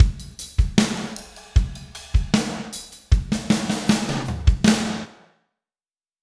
Spaced Out Knoll Drums Ending.wav